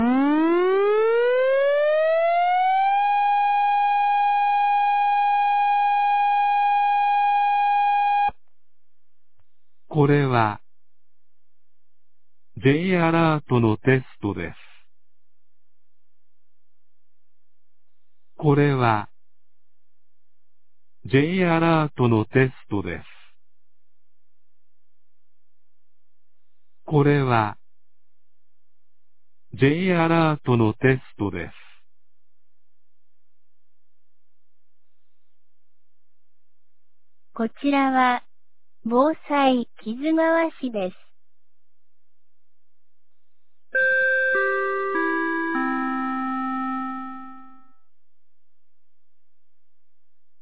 2025年02月12日 11時01分に、木津川市より市全域へ放送がありました。
放送音声